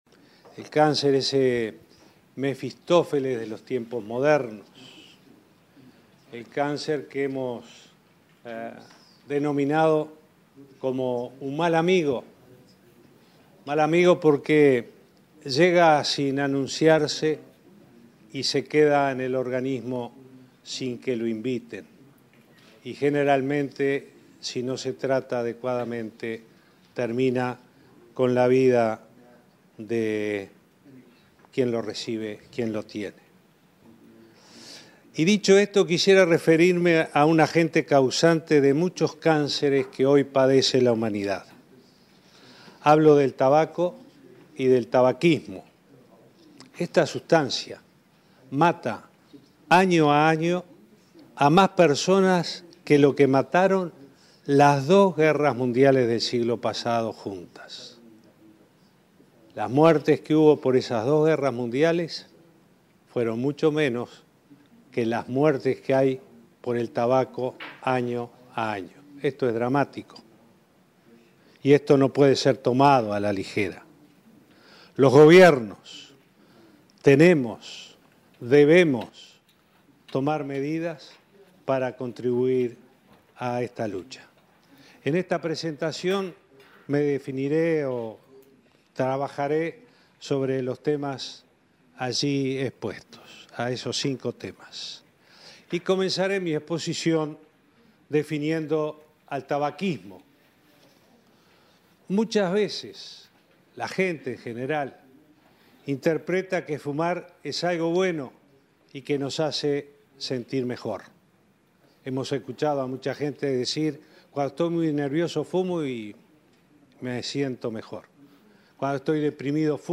Posteriormente realizó una presentación en un encuentro con representantes de la organización Forum Rauchfrei donde repasó las políticas uruguayas y reafirmó el compromiso de profundizar las medidas en beneficio de la salud de la población.